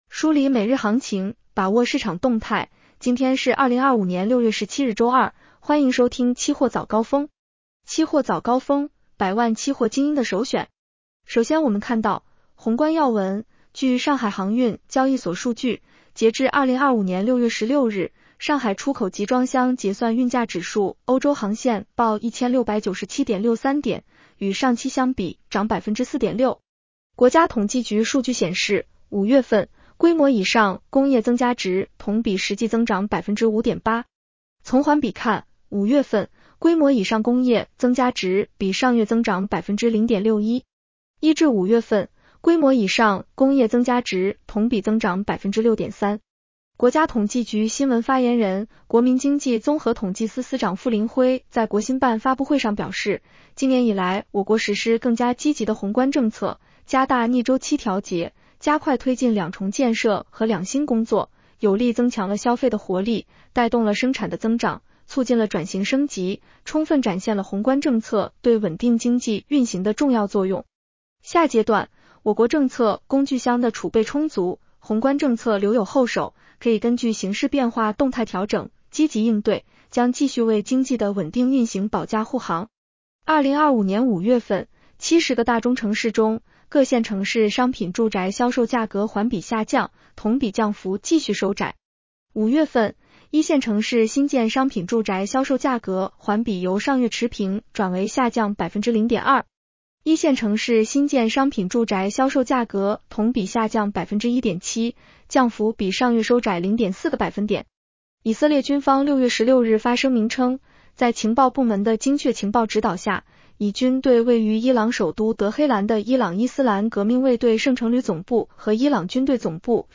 期货早高峰-音频版
期货早高峰-音频版 女声普通话版 下载mp3 宏观要闻 1.